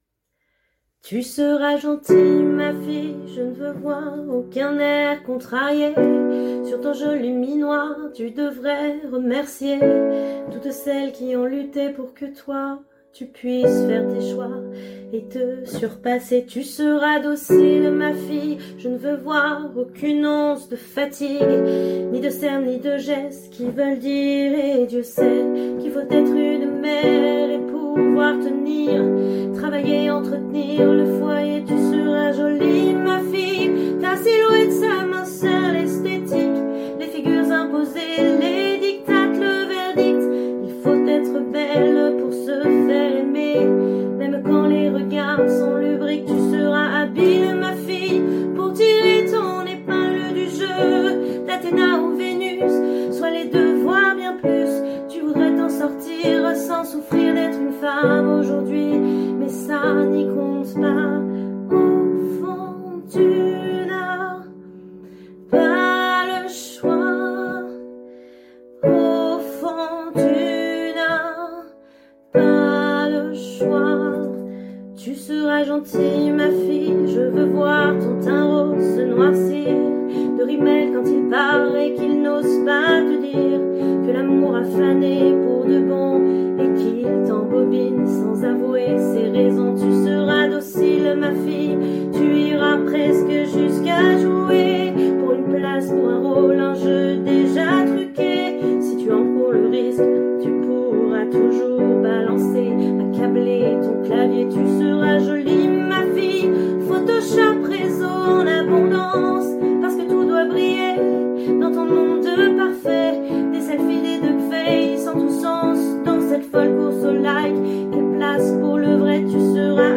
Démo chanteuse
20 - 61 ans - Mezzo-soprano